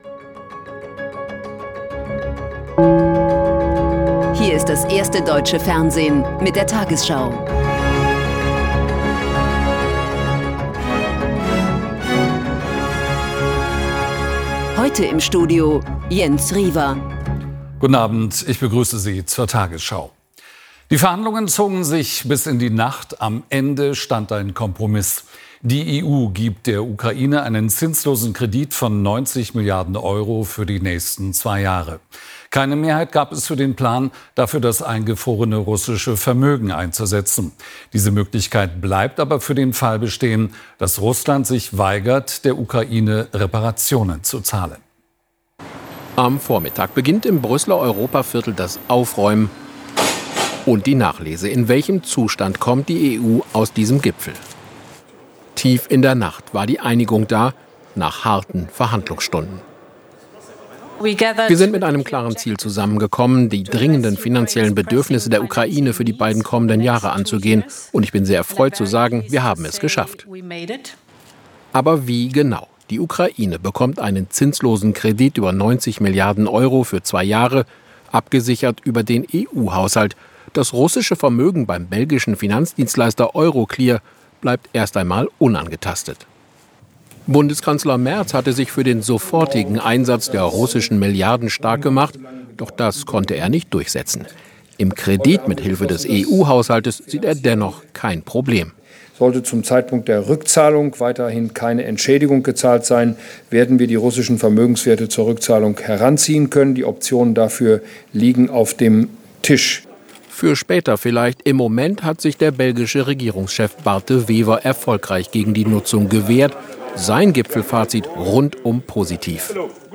tagesschau 20:00 Uhr, 19.12.2025 ~ tagesschau: Die 20 Uhr Nachrichten (Audio) Podcast